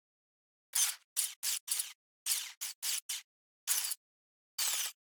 Sfx_tool_hoverpad_build_arm_left_servo_loop_01.ogg